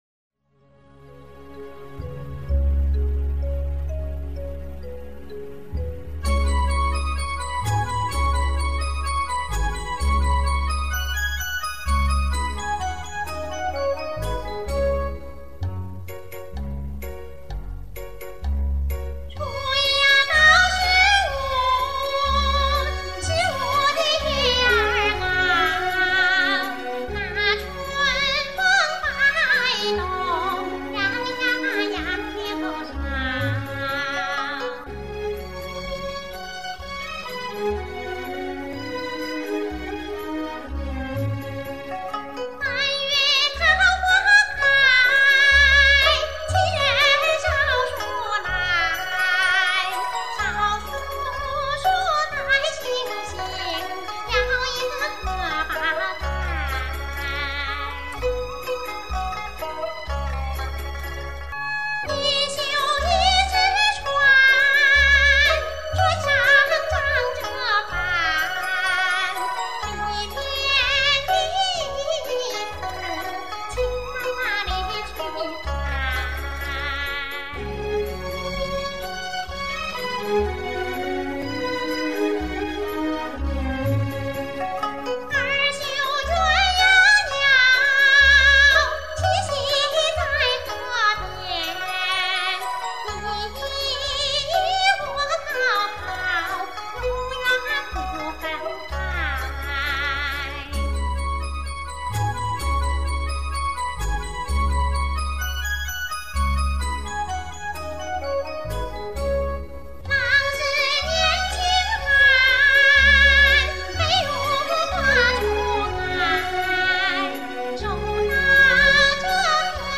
【三月桃花开】音乐会 山西民歌《绣荷包》